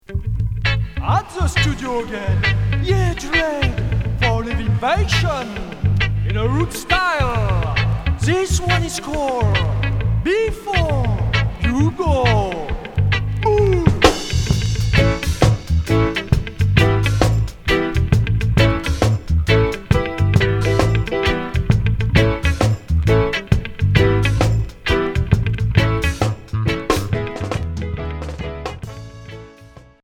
Reggae / rocksteady Unique 45t retour à l'accueil